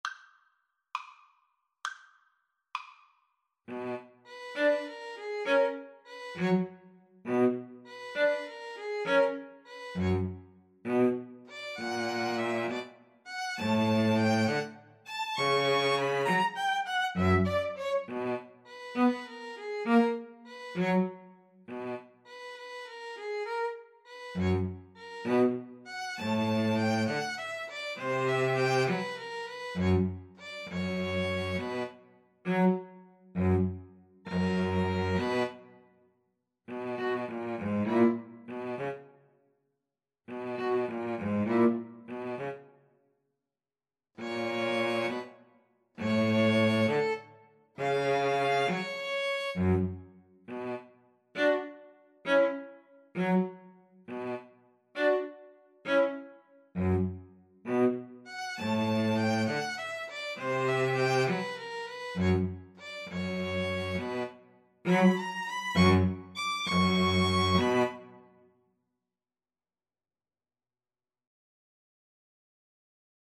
Moderato
6/8 (View more 6/8 Music)
Classical (View more Classical String trio Music)